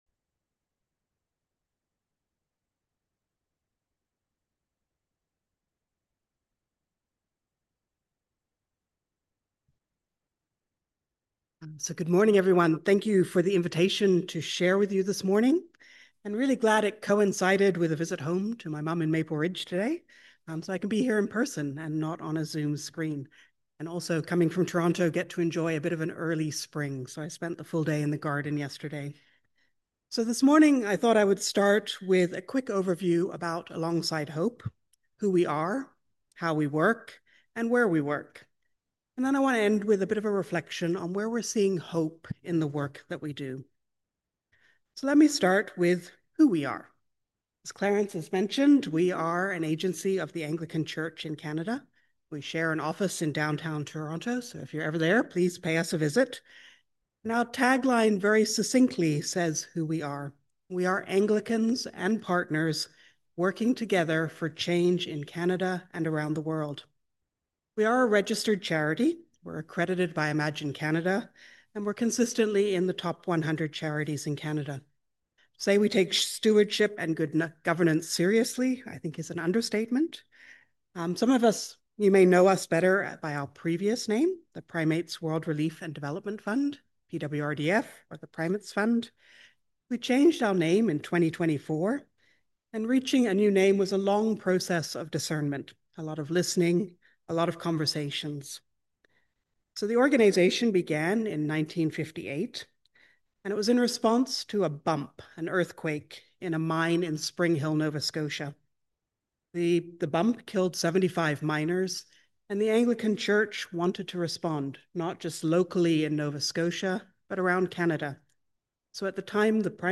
Sermon on the Fifth Sunday in Lent / Alongside Hope Sunday